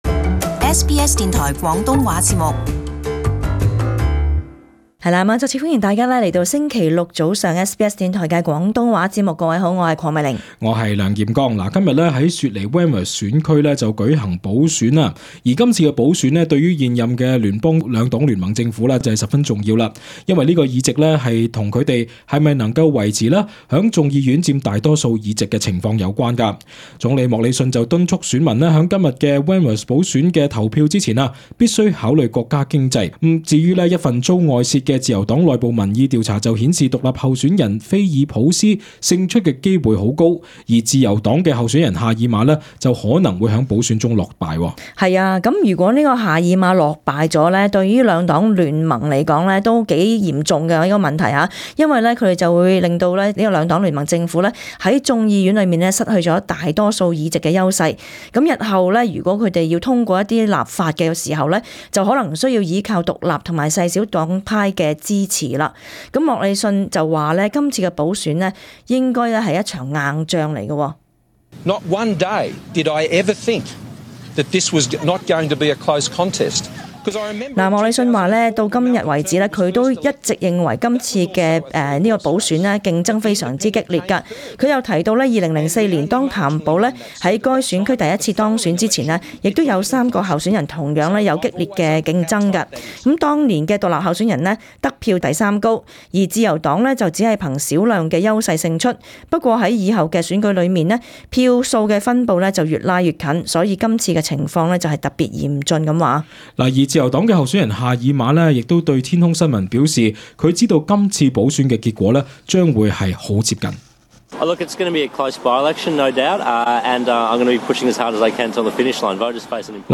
【時事報導】自由黨對Wentworth選區補選不予厚望